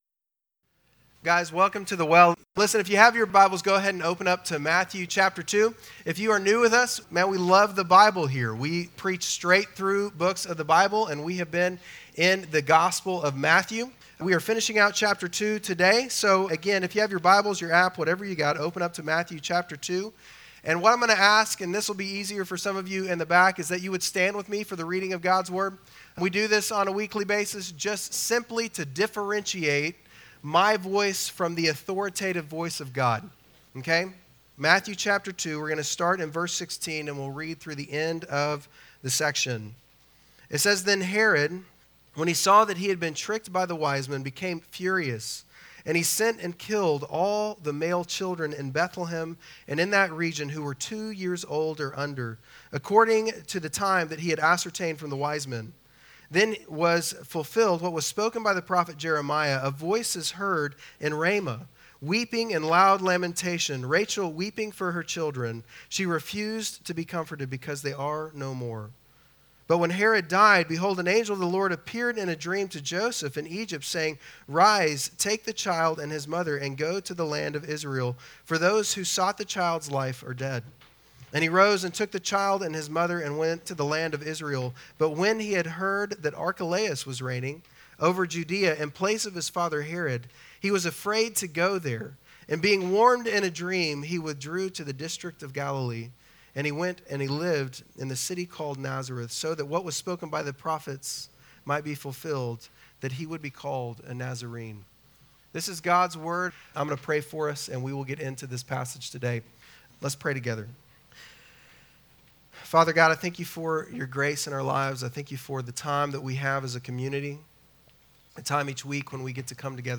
Bible Text: Matthew 2:19-23 | Preacher